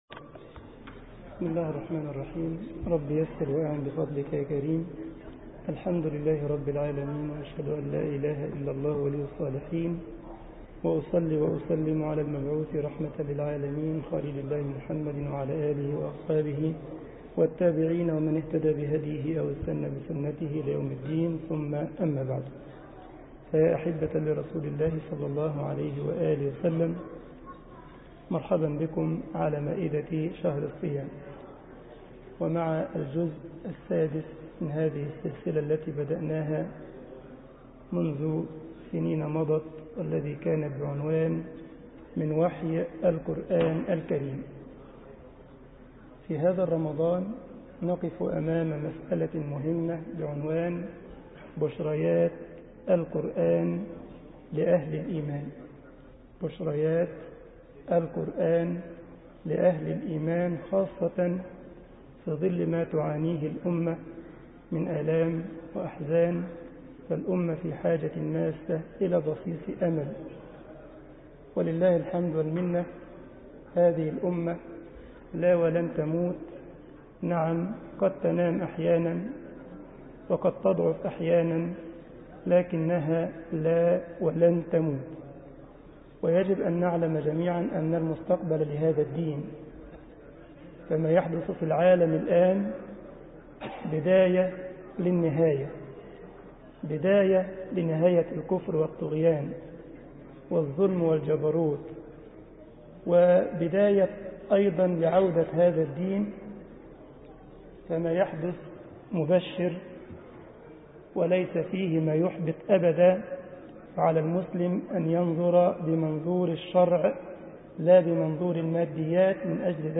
مسجد الجمعية الإسلامية بالسارلند ـ ألمانيا
درس 01 رمضان 1433 هـ الموافق 20 يوليو 2012 م